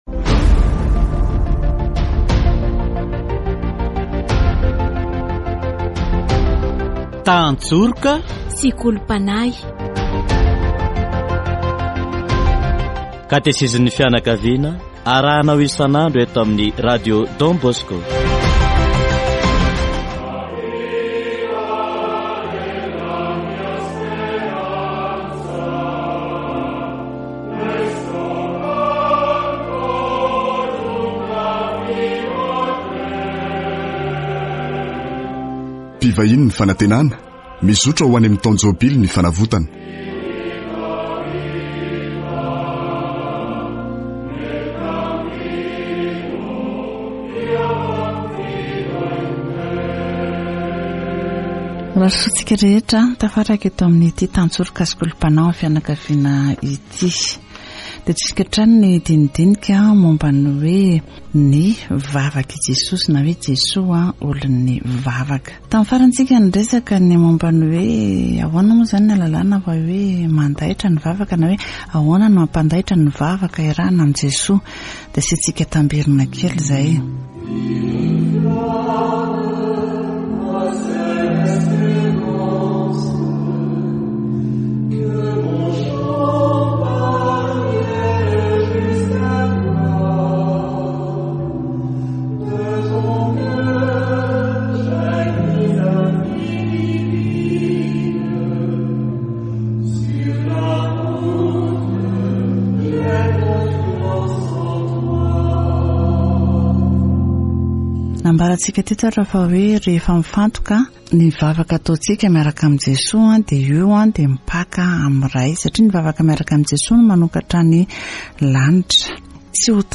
Katesizy momba an'i Jesoa, olon'ny vavaka